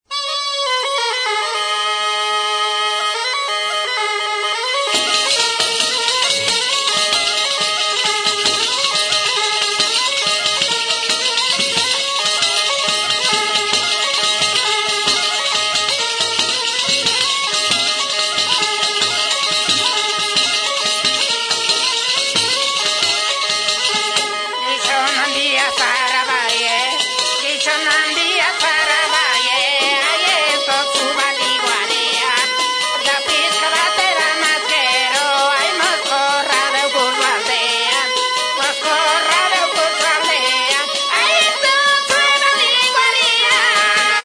Instrumentos de músicaAlboka
Aerófonos -> Lengüetas -> Simple (clarinete)
ALBOKA
Klarinete bikoitza da.